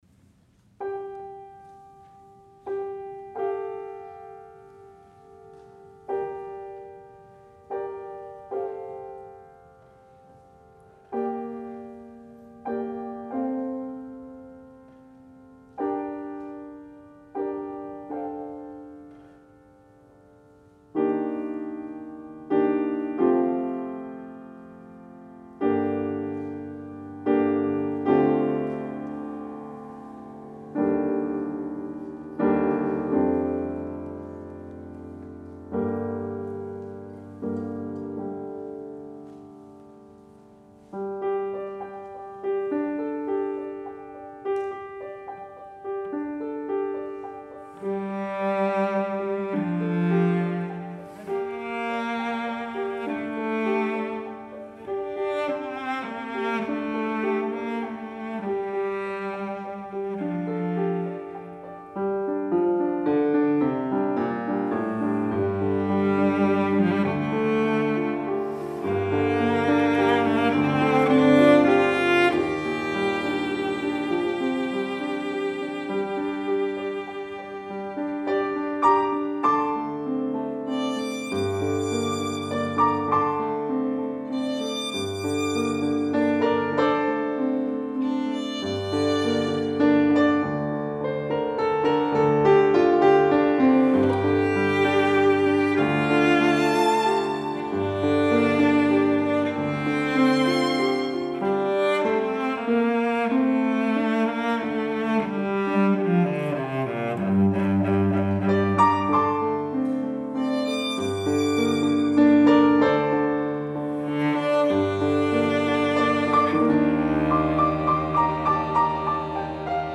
for Piano Trio (2015)